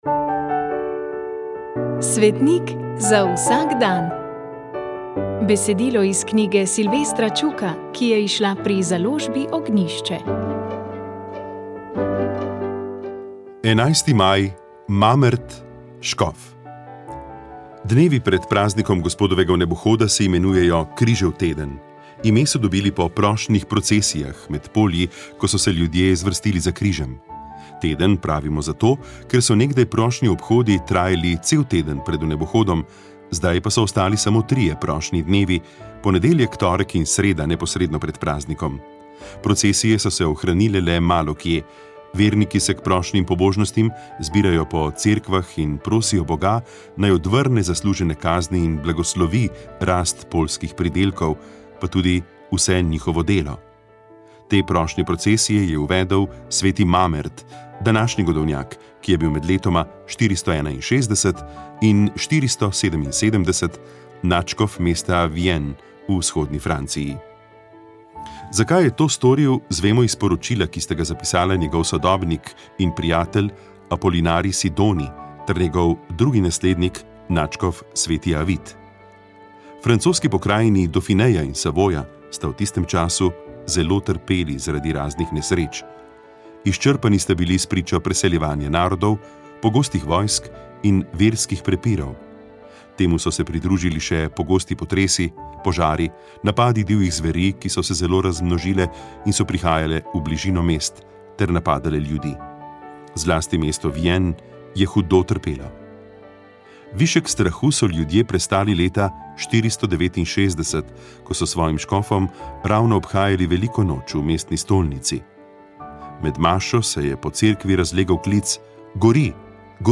Sveta maša
Sv. maša iz cerkve Marijinega oznanjenja na Tromostovju v Ljubljani 12. 12.
prepeval je moški pevski zbor